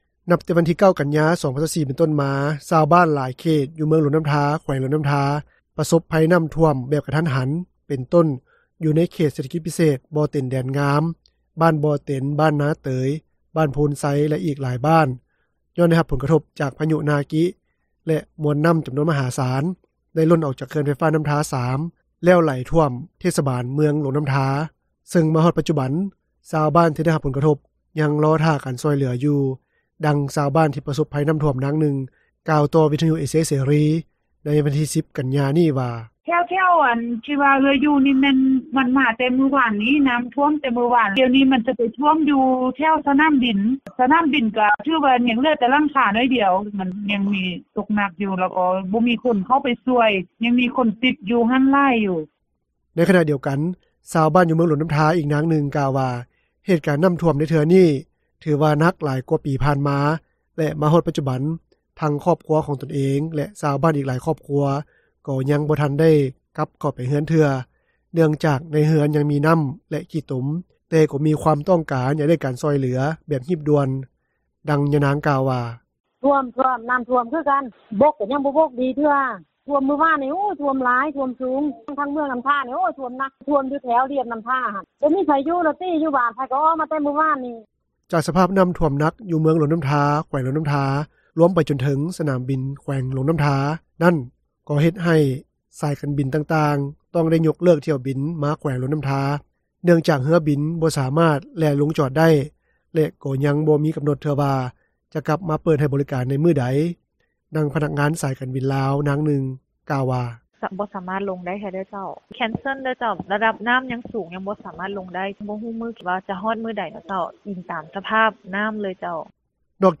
ດັ່ງຊາວບ້ານ ທີ່ປະສົບໄພນ້ຳຖ້ວມ ນາງໜຶ່ງ ກ່າວຕໍ່ວິທຍຸເອເຊັຽເສຣີ ໃນວັນທີ 10 ກັນຍາ ນີ້ວ່າ:
ດັ່ງພະນັກງານ ສາຍການບິນລາວ ນາງໜຶ່ງ ກ່າວວ່າ:
ດັ່ງເຈົ້າໜ້າທີ່ ພາກປະຊາສັງຄົມ ນາງໜຶ່ງ ກ່າວວ່າ: